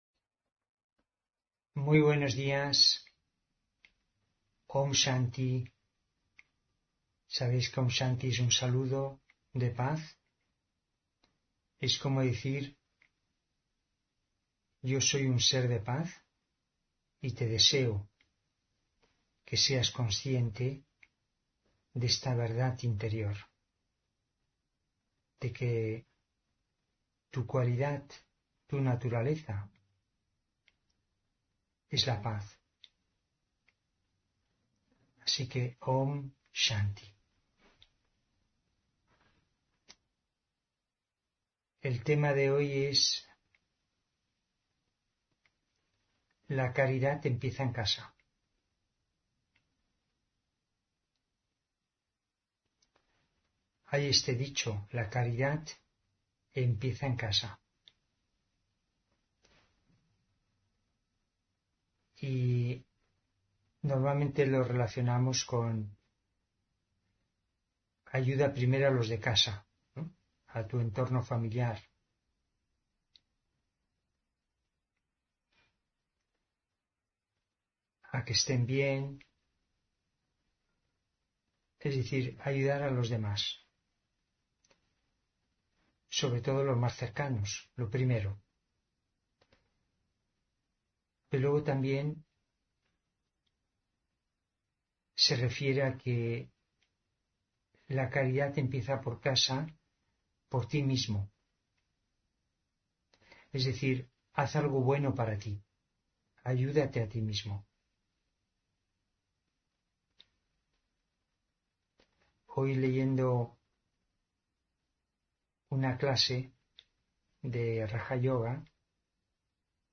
Meditación de la mañana: Confía en ti